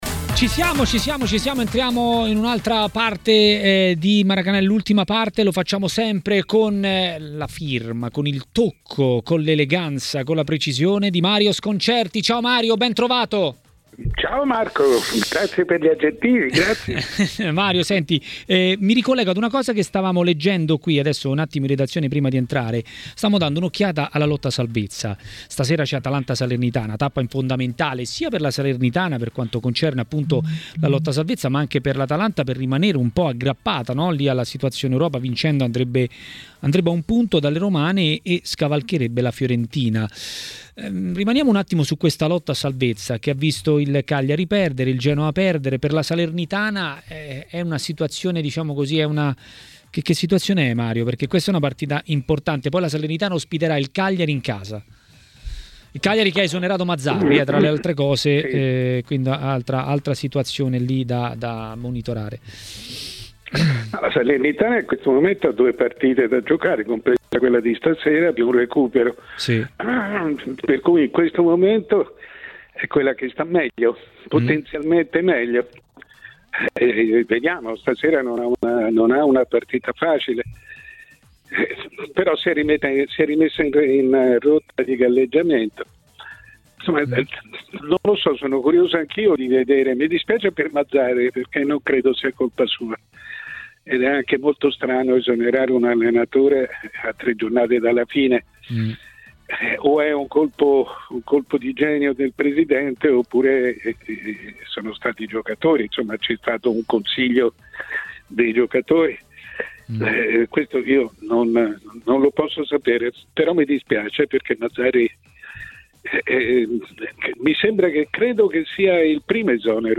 Il giornalista Mario Sconcerti a TMW Radio, durante Maracanà, ha commentato la giornata di Serie A.